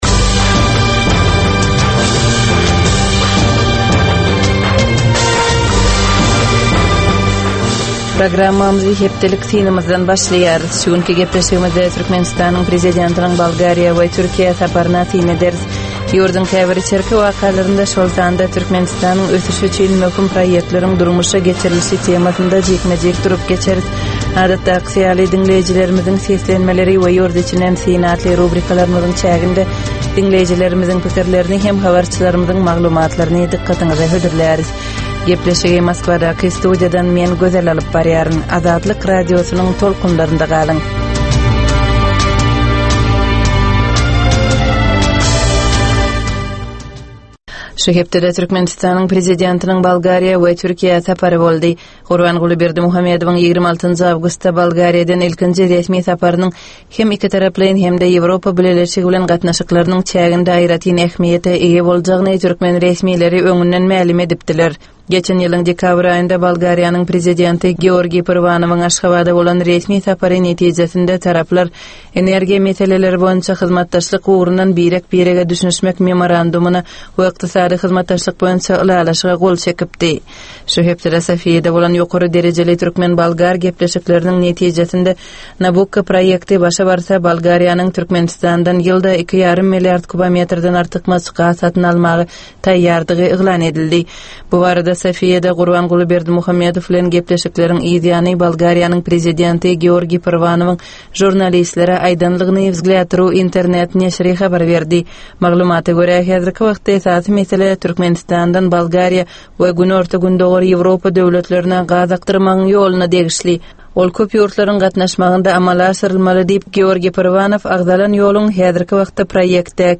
Tutus geçen bir hepdänin dowamynda Türkmenistanda we halkara arenasynda bolup geçen möhüm wakalara syn. 25 minutlyk bu ýörite programmanyn dowamynda hepdänin möhüm wakalary barada gysga synlar, analizler, makalalar, reportažlar, söhbetdeslikler we kommentariýalar berilýar.